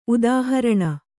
♪ udāharaṇa